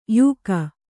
♪ yūka